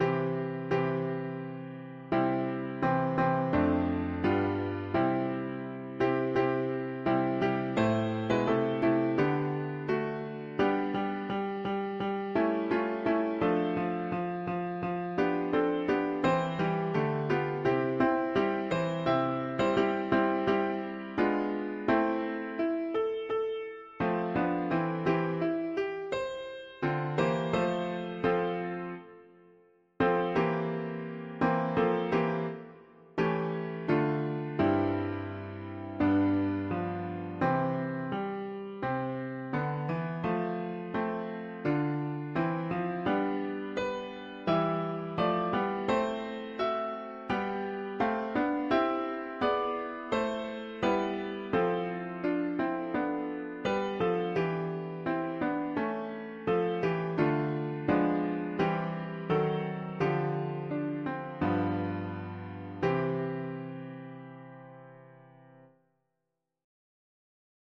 the Lord make his face to shine upon … english theist 4part
Key: C major Meter: irregular